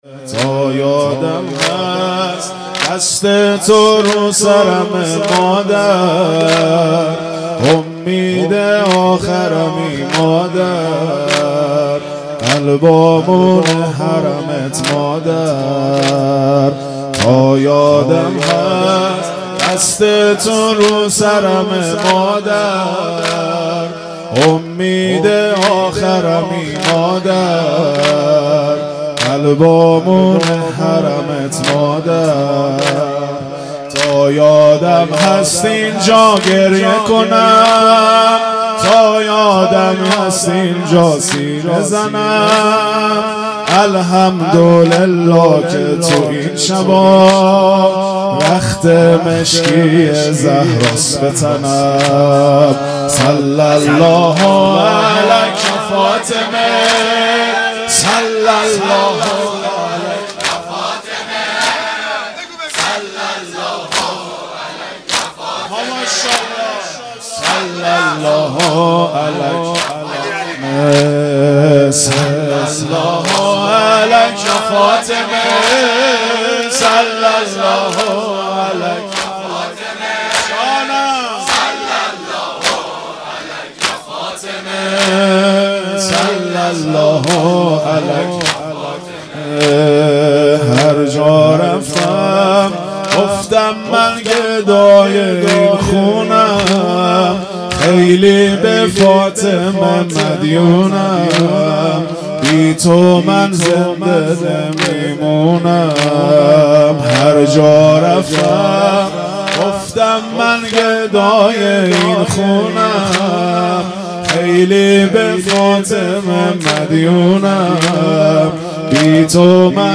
فاطمیه دوم_شب ششم